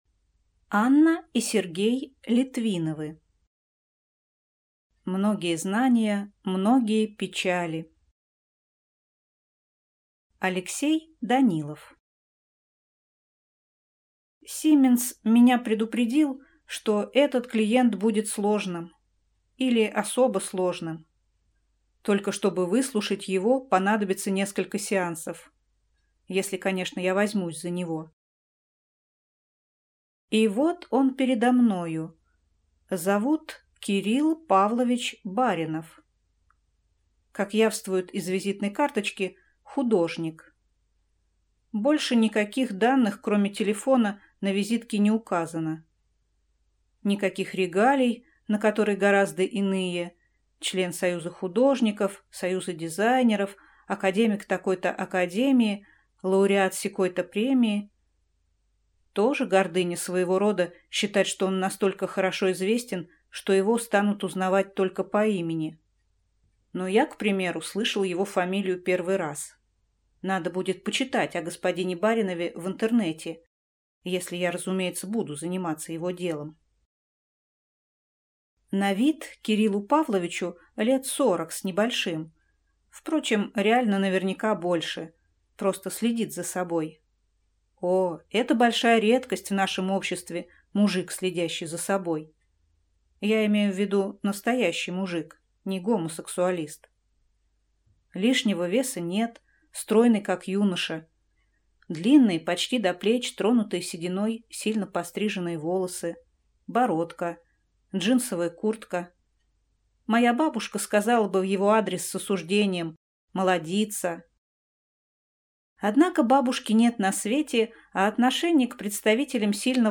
Аудиокнига Многие знания – многие печали | Библиотека аудиокниг